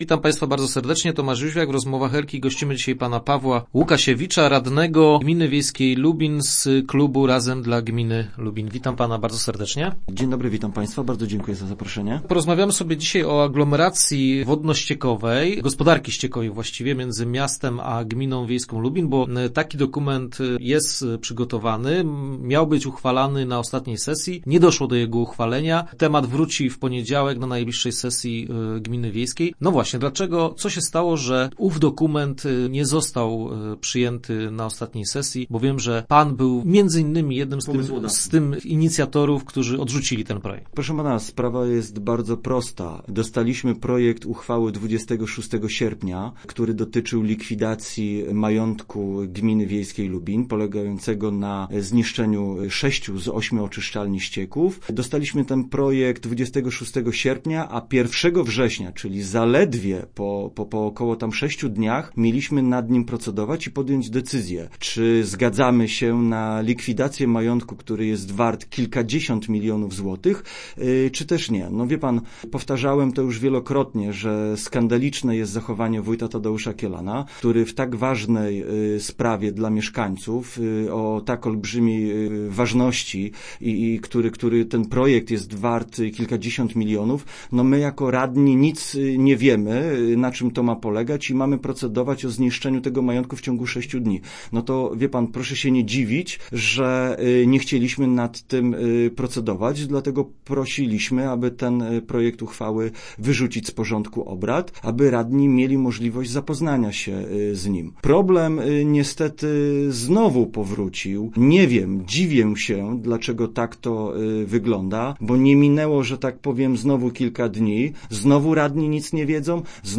Naszym go�ciem by� radny Pawe� �ukasiewicz, który podnosi sens powo�ania takiej aglomeracji.